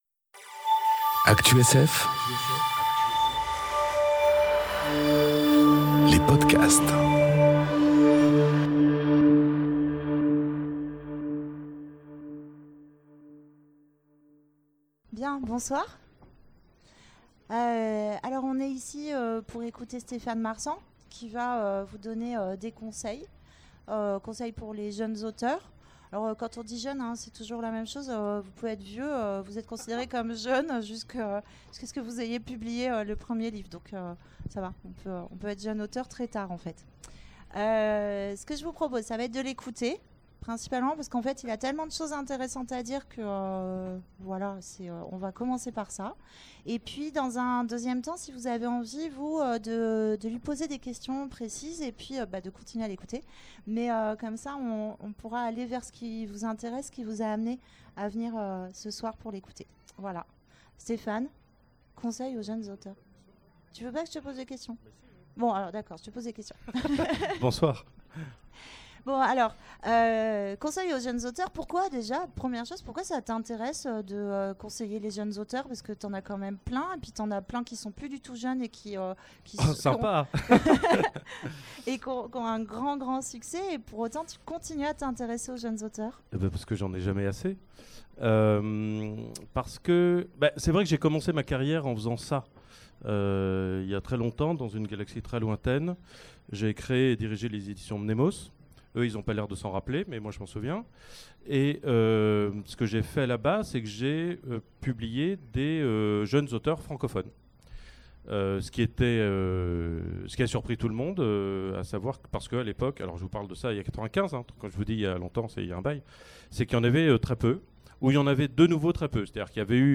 Conférence Conseils aux jeunes auteurs enregistrée aux Imaginales 2018